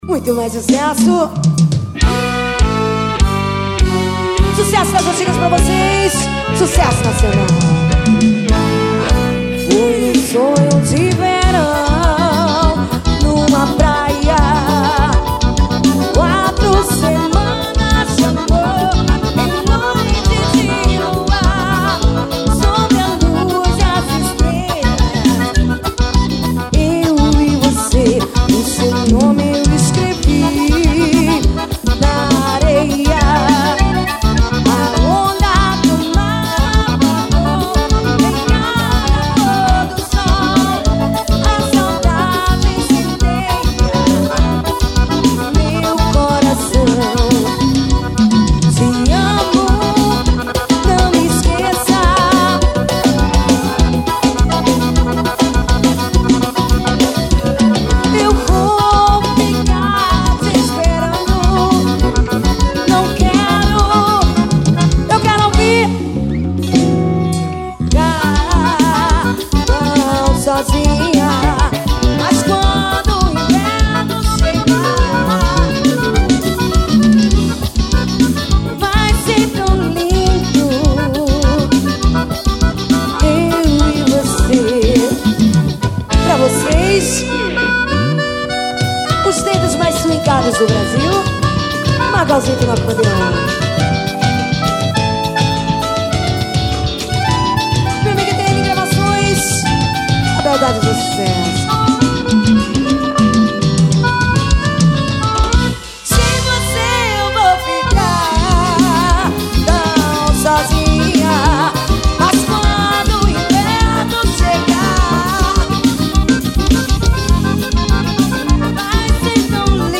é uma música